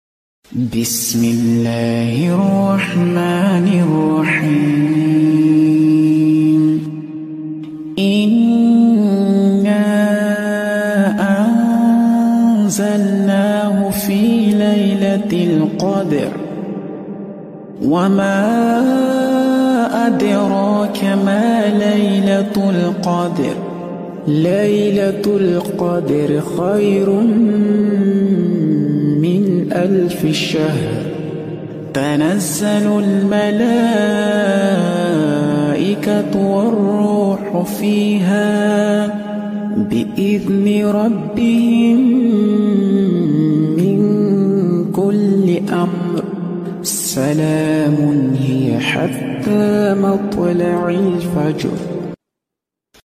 Murottal Suara Merdu Surah 97 sound effects free download